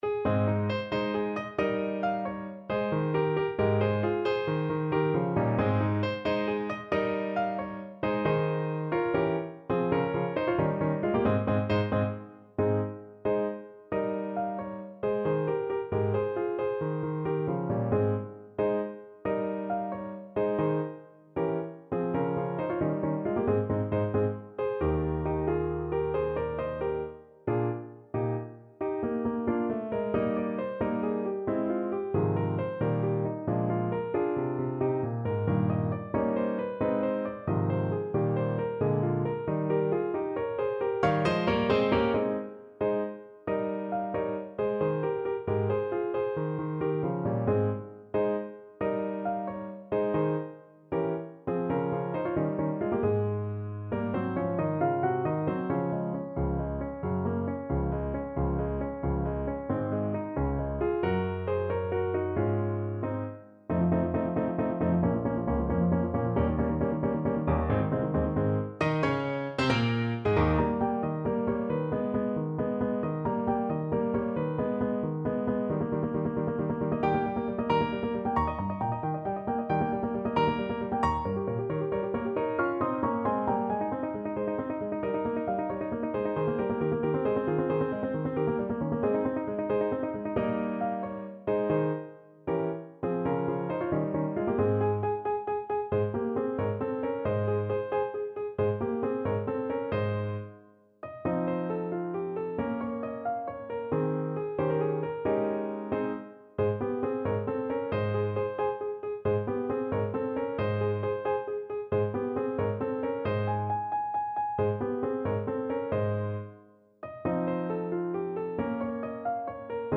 6/8 (View more 6/8 Music)
. = 90 Allegretto vivace
Classical (View more Classical Saxophone Music)
cosi_amore_ladroncello_ASAX_kar1.mp3